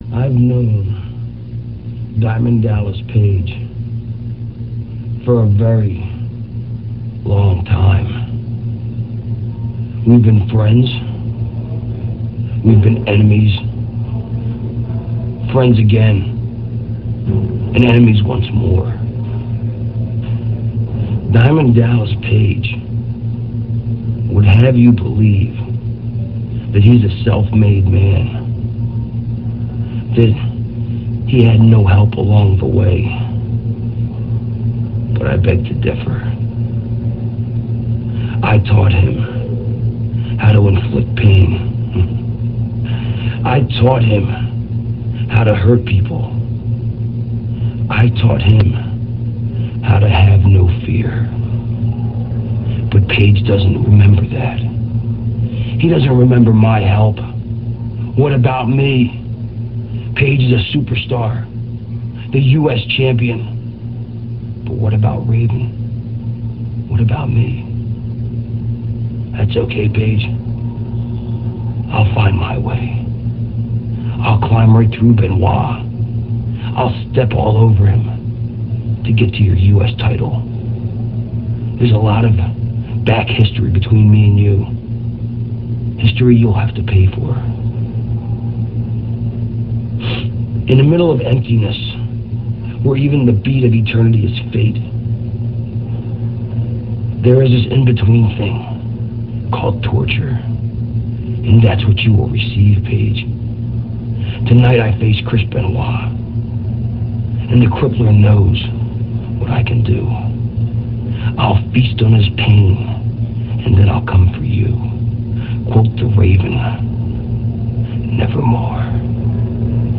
- This speech comes from WCW Saturday Night - [2.28.98]. Raven talking about how he is responsible for DDP's success and he will climb through Benoit to get to DDP's U.S. title.